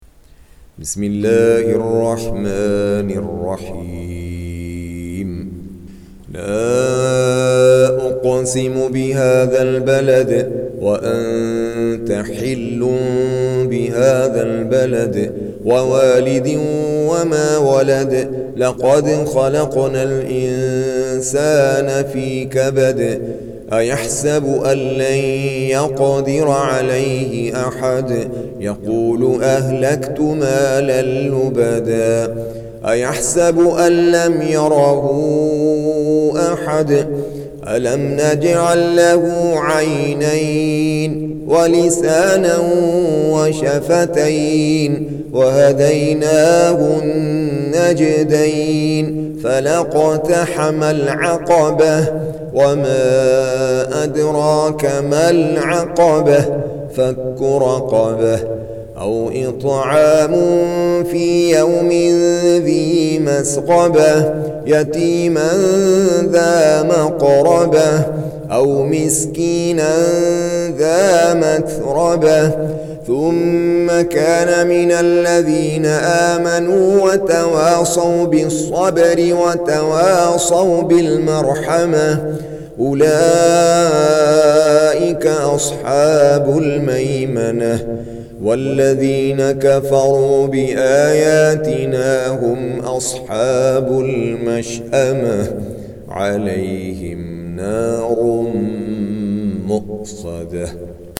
Surah Sequence تتابع السورة Download Surah حمّل السورة Reciting Murattalah Audio for 90. Surah Al-Balad سورة البلد N.B *Surah Includes Al-Basmalah Reciters Sequents تتابع التلاوات Reciters Repeats تكرار التلاوات